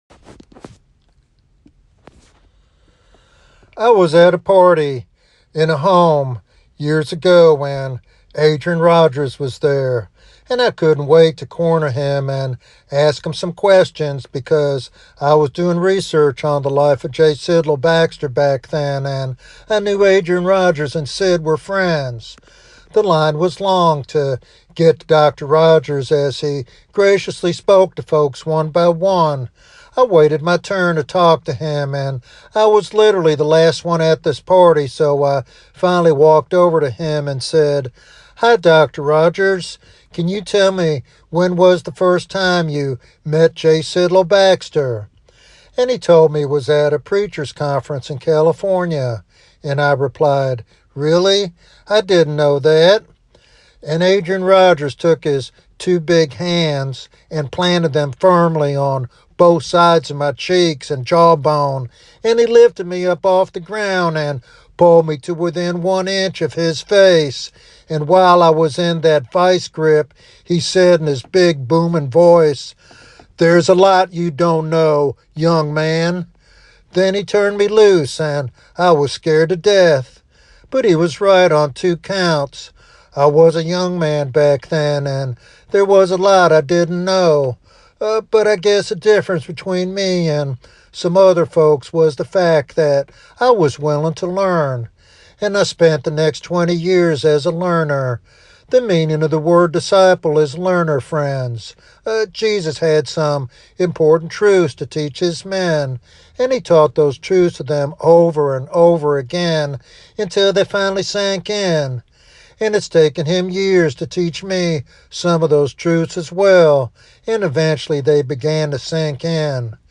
This sermon challenges believers to embrace humility as the path to spiritual growth and effective service.